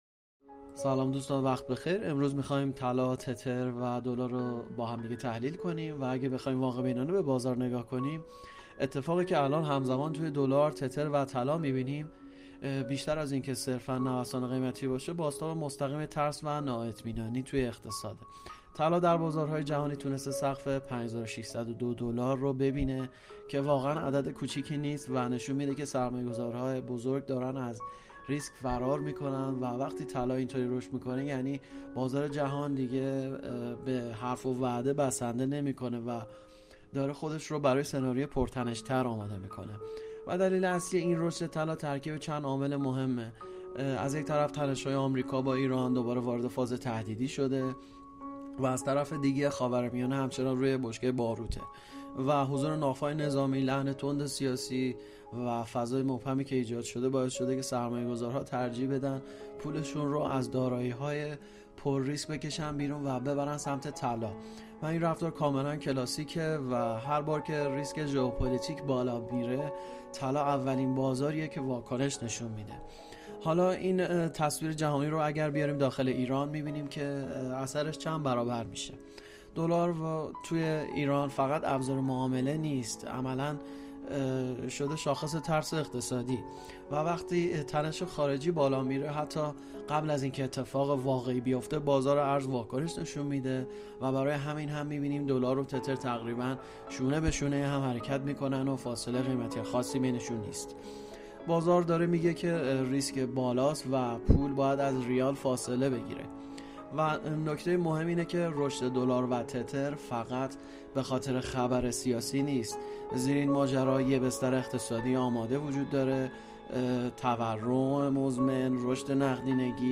🔸 گروه مالی و تحلیلی Eagle Economic با تحلیل‌های صوتی تخصصی بازار ارز و طلا در کنار شماست. در این بخش، تمرکز اصلی ما روی بررسی دقیق وضعیت دلار، تتر و طلای جهانی و ارتباط آن‌ها با شرایط اقتصادی و تحولات سیاسی دنیاست؛ تحلیلی که به شما کمک می‌کند تصویر واضح‌تری از مسیر بازار داشته باشید.
🎧 این تحلیل‌ها به‌صورت کاملاً روان و قابل فهم ارائه می‌شوند تا بتوانید بدون پیچیدگی‌های اضافی، تصمیمات آگاهانه‌تری برای مدیریت سرمایه و معاملات خود بگیرید.